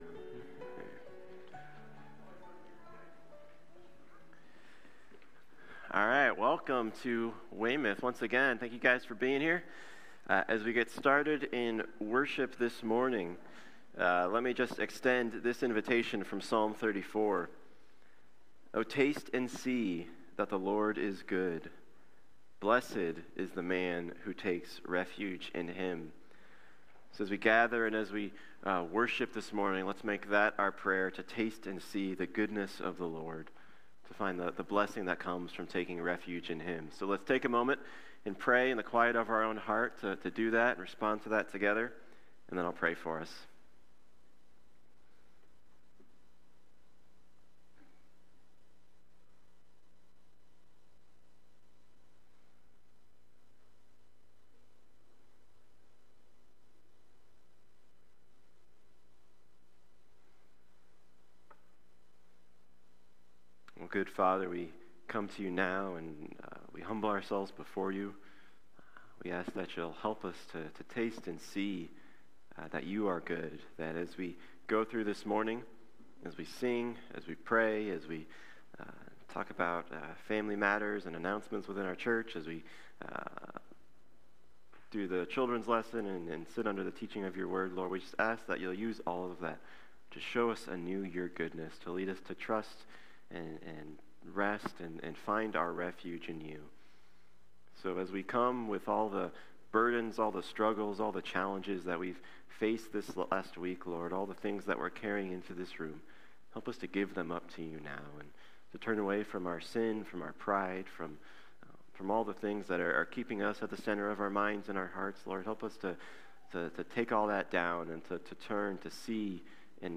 1 Peter 3:8-12 Service Type: Sunday Morning Do good